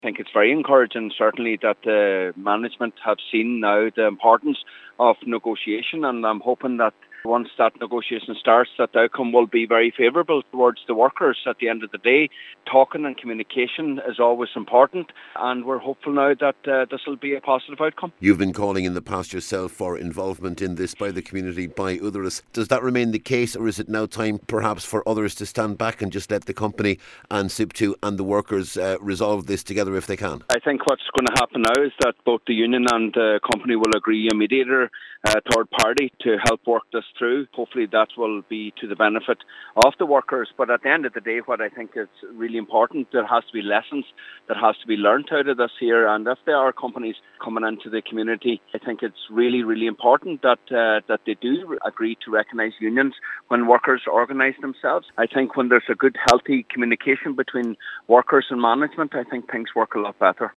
Local Cllr Michael Cholm Mac Giolla Easbuig expects a third party mediator will become involved……….